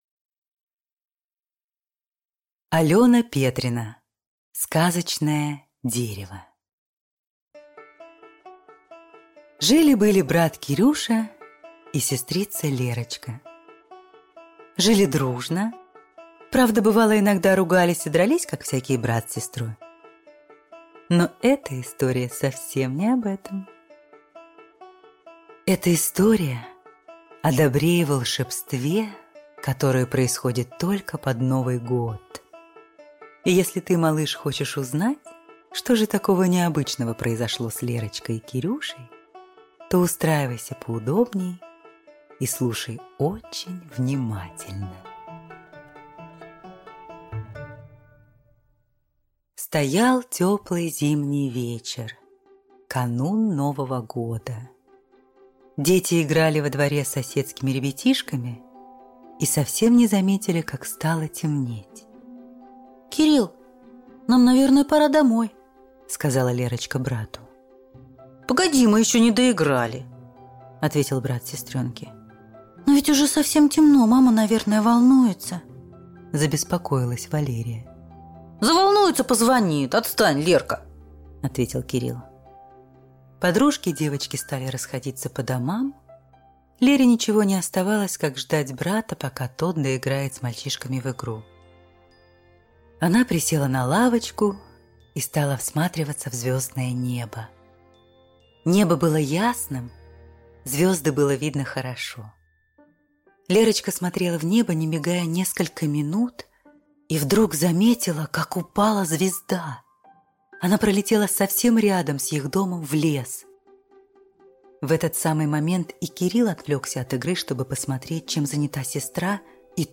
Аудиокнига Сказочное дерево | Библиотека аудиокниг